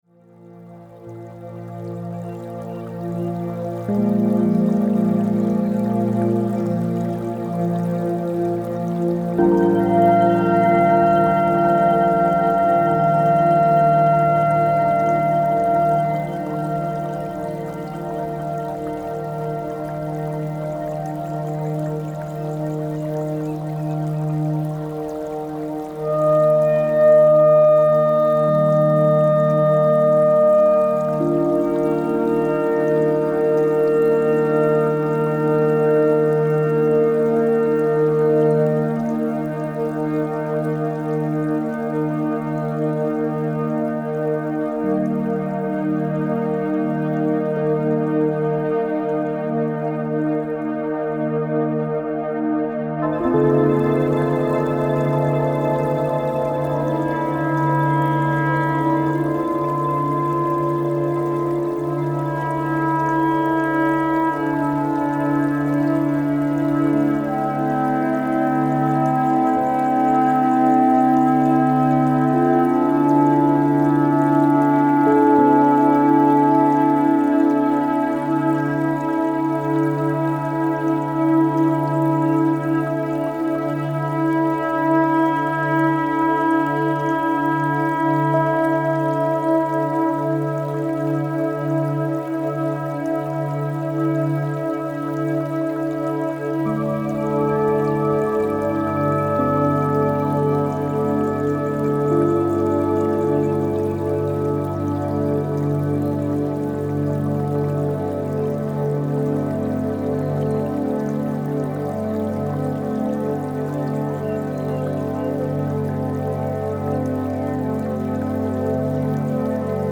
Медитация